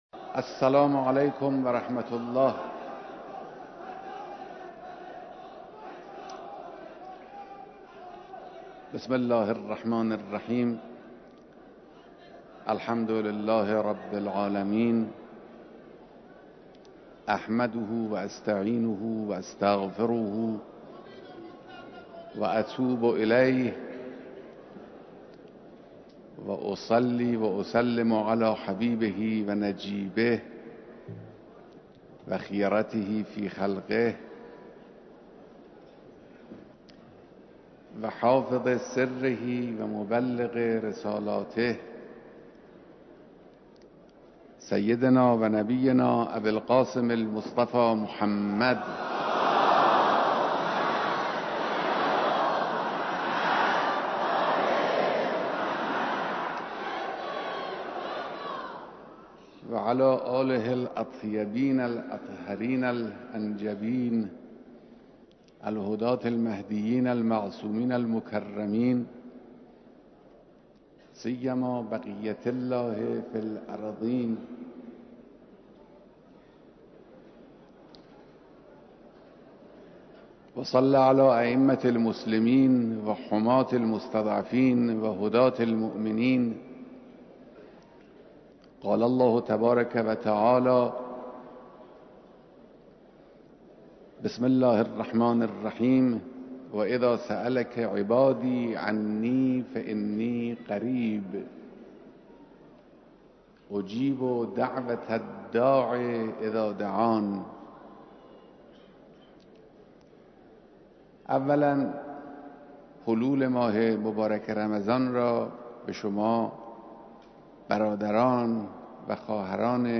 بیانات در خطبه‌های نمازجمعه‌